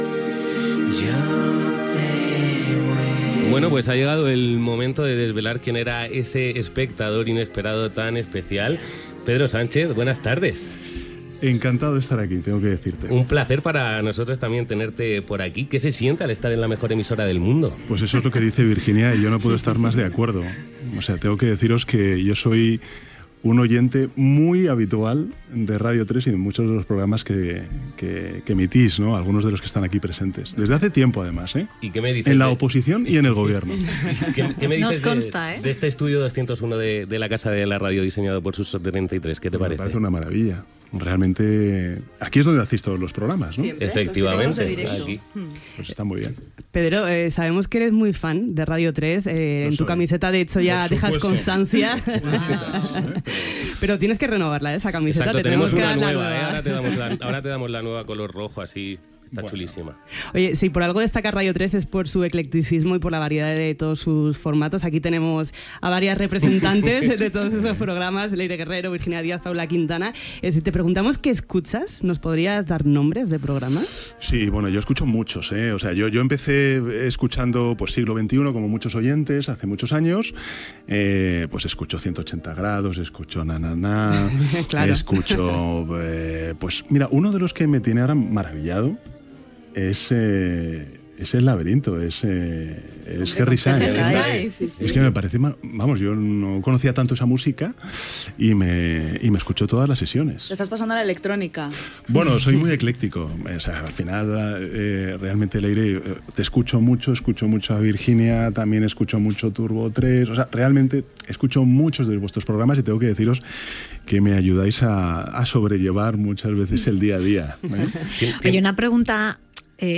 Fragment d'una entrevista al president del Govern espanyol Pedro Sánchez, que explica que escolta Radio 3